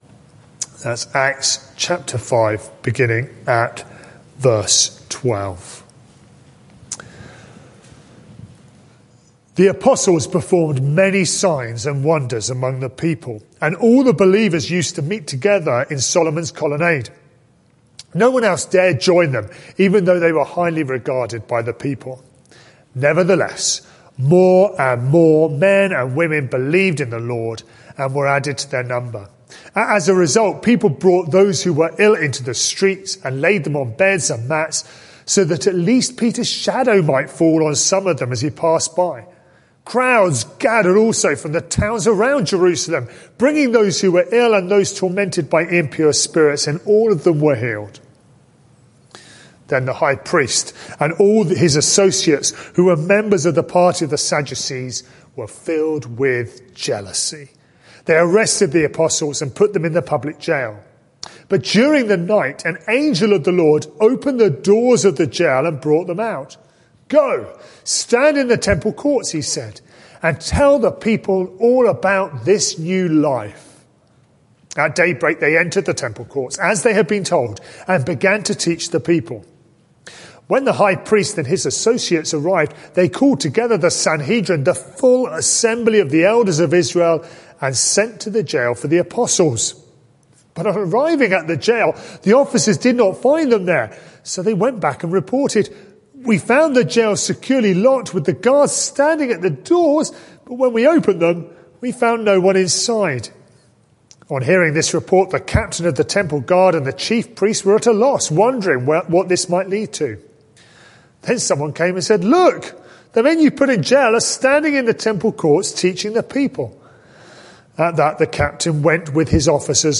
This sermon is part of a series